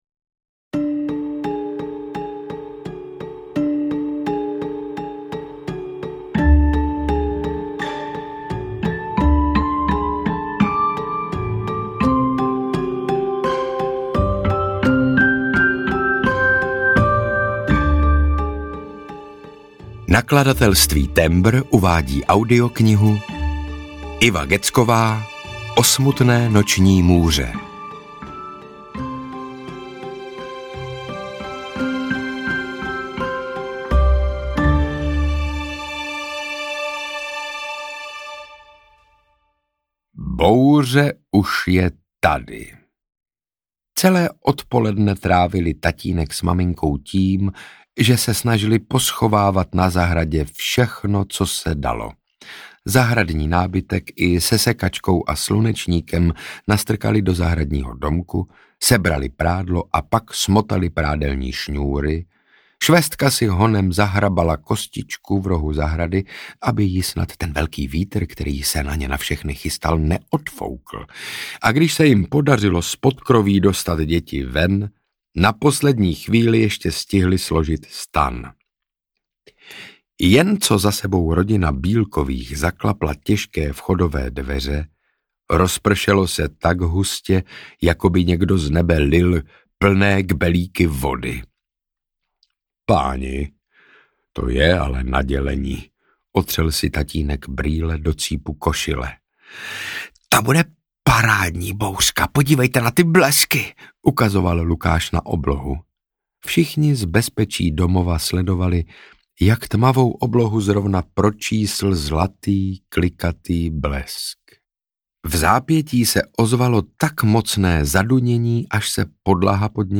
Ukázka z knihy
• InterpretSaša Rašilov